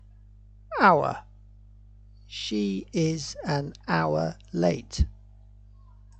(h is silent – pronounced our. No vowel sound so no a nor an.)